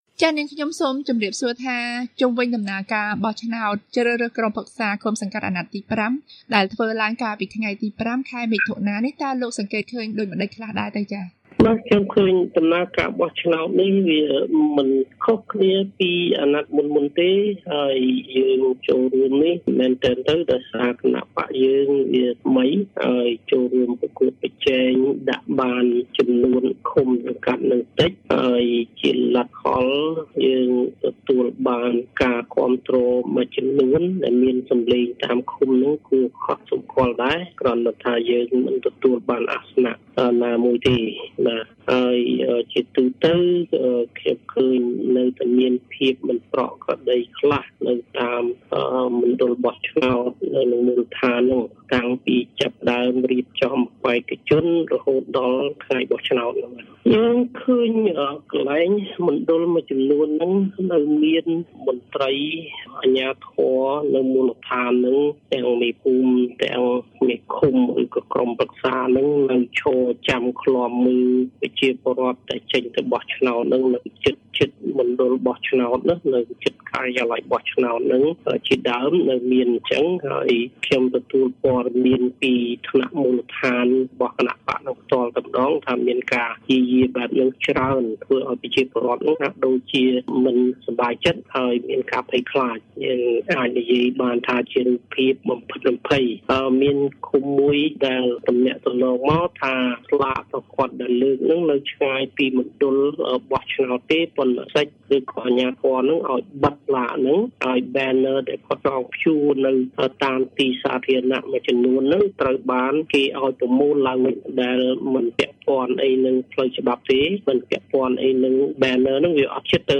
បទសម្ភាសន៍ VOA៖ គណបក្សកែទម្រង់កម្ពុជាថា ការបែកខ្ញែកសំឡេងក្រុមមន្ត្រីអតីតបក្សប្រឆាំង ធ្វើឱ្យប៉ះពាល់ដល់សំឡេងអ្នកគាំទ្រ